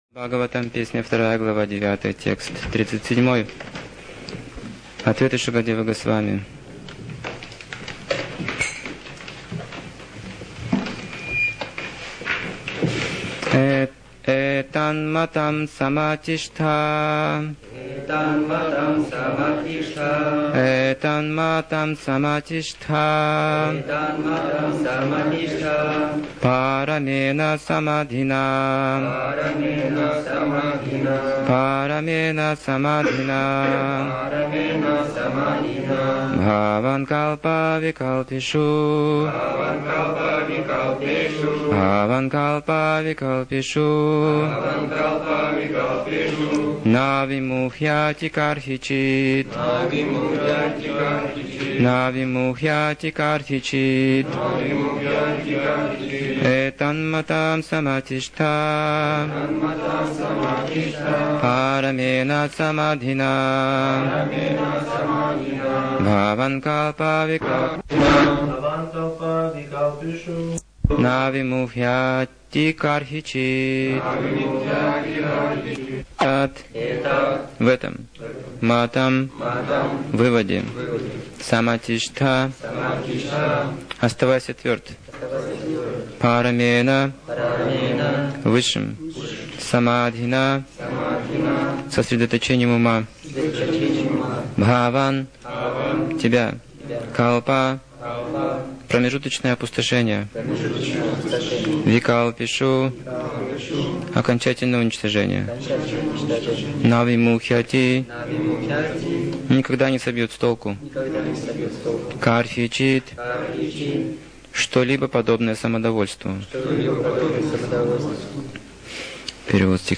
Темы, затронутые в лекции: Счастье преданного служения Материальная природа Постижение процесса творения Истинная передача знания Милость Прабхупады Характер наставлений Наставления Прабхупады Причины оскорблений Дух проповеди Необходимость смирения Важность сотрудничества